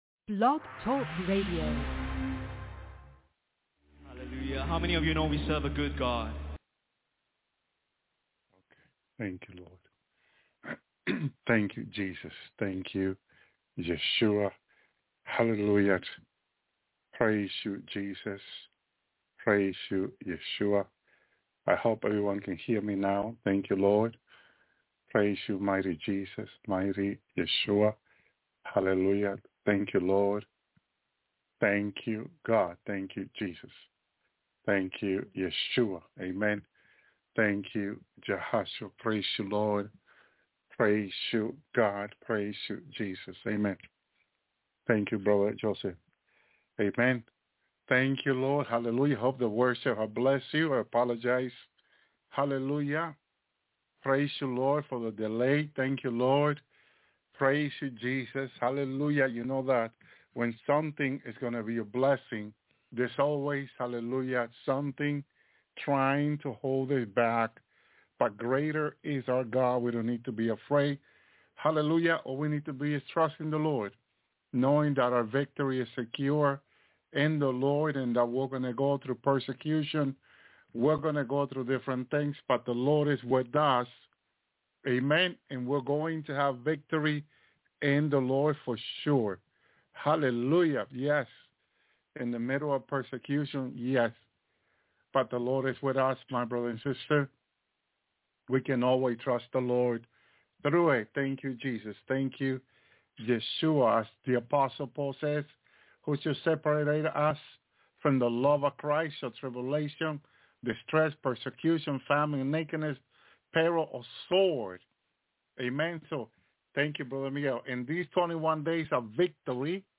live-bible-studyccp-of-china-will-be-save-said-the-lord-jesus.mp3